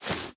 Minecraft Version Minecraft Version snapshot Latest Release | Latest Snapshot snapshot / assets / minecraft / sounds / block / roots / step5.ogg Compare With Compare With Latest Release | Latest Snapshot
step5.ogg